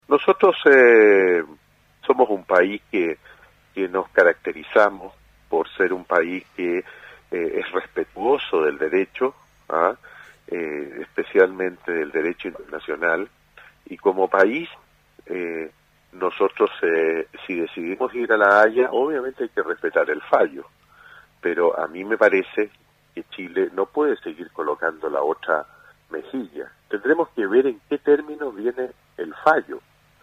En conversación con Radio Sago, el Senador,  Iván Moreira,   sugirió hoy que el país estudie retirarse del Pacto de Bogotá después que la Corte Internacional de Justicia de La Haya emita su fallo sobre la demanda que Bolivia  presentó contra Chile por soberanía marítima.